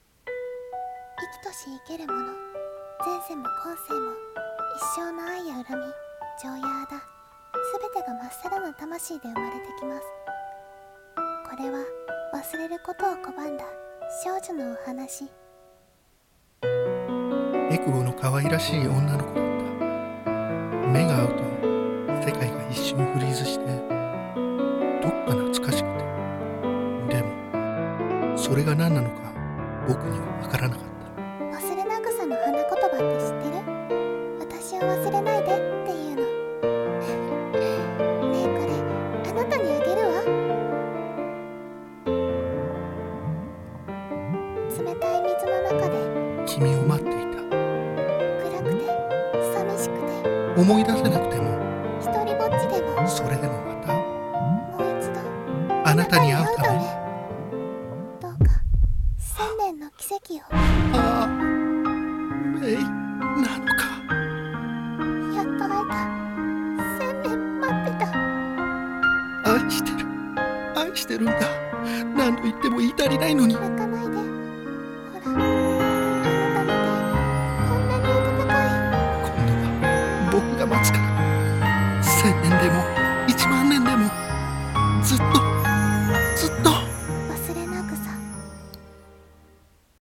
CM風声劇「勿忘草」